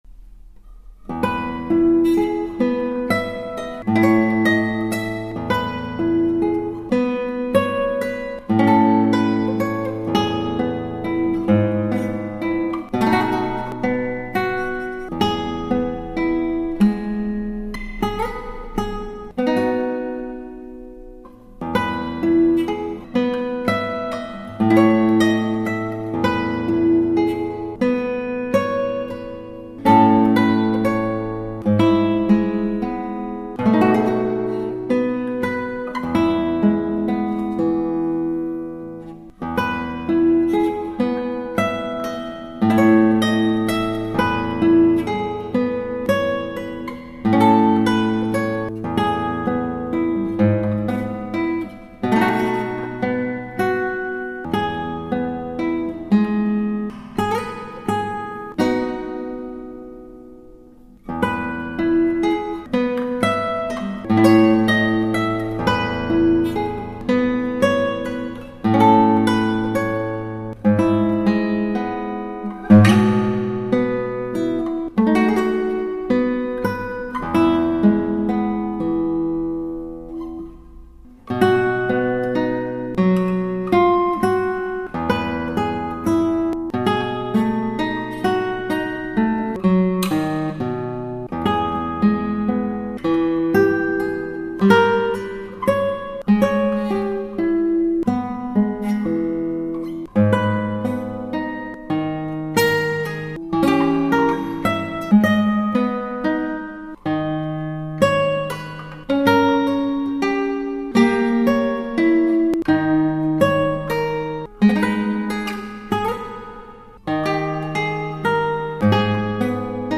ギターはアルカンヘルで